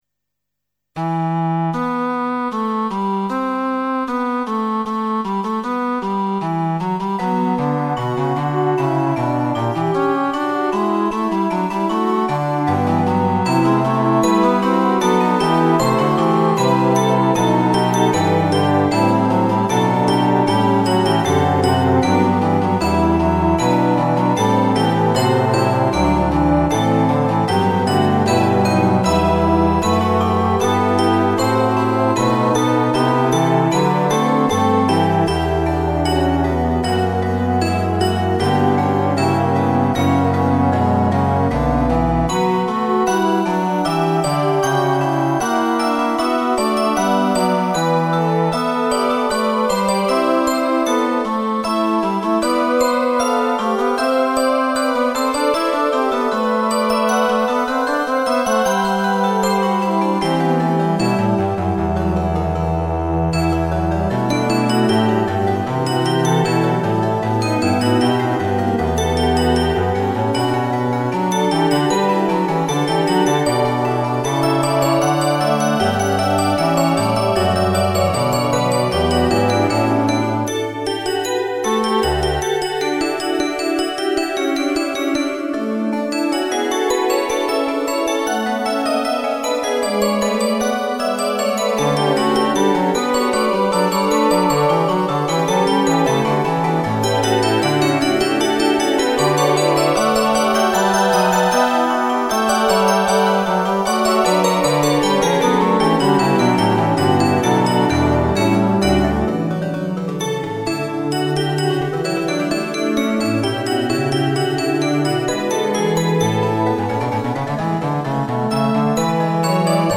Prelude en fuga nr 1 in e
Prelude met een obstinate bas en een mystiek tintje.
Prelude with a basso obstinato and mystic flavour (to be supplied yet).
Fuga1.mp3